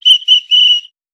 Whistle Rapid Blow.wav